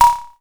REDD PERC (31).wav